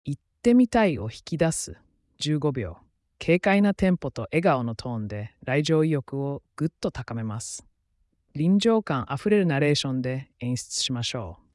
WEGEE Voice Studio は、テキストから自然な日本語音声をつくるスタジオ。
軽快なテンポと笑顔のトーンで、来場意欲をぐっと高めます。
臨場感あふれるナレーションで演出しましょう。
VOICE：やさしい男性
VIBE：ニュートラル（標準）